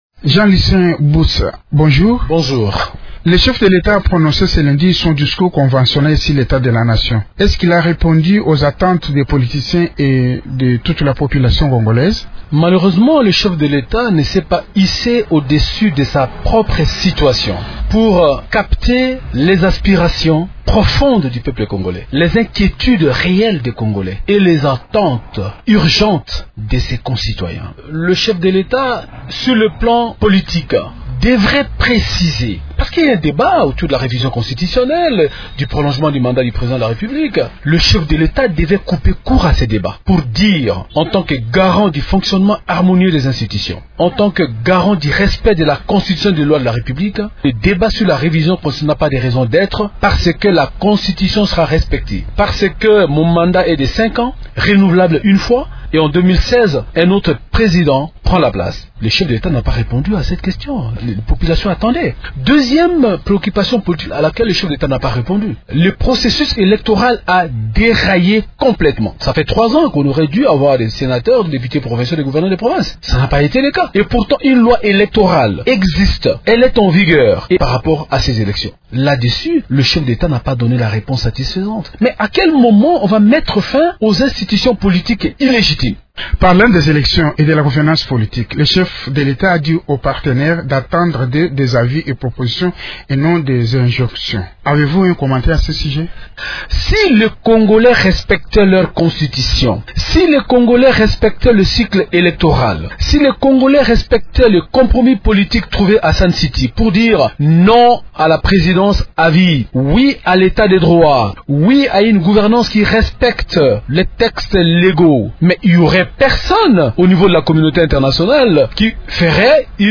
Au lendemain du discours du chef de l’Etat Joseph Kabila devant les deux chambres du Parlement réunies en congrès, le député de l’opposition Jean Lucien Busa, est l’invité de Radio Okapi.